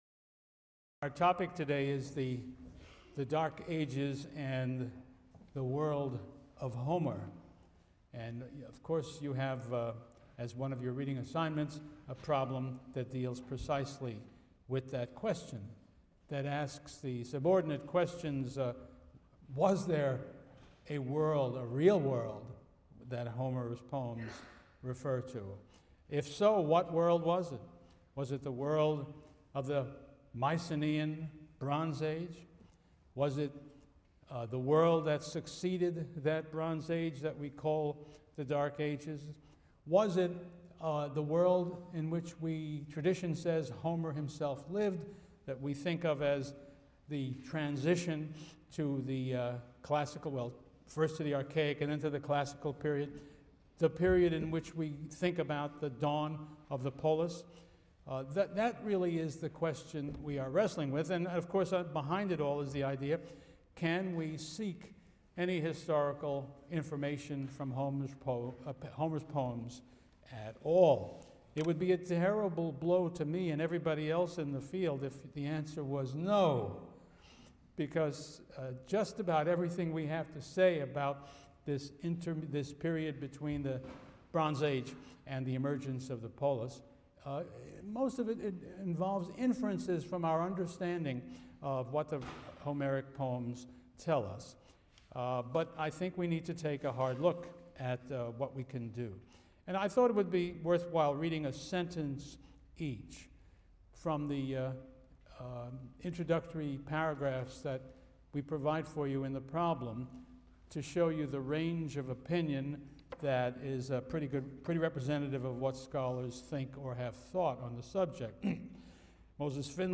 CLCV 205 - Lecture 3 - The Dark Ages (cont.)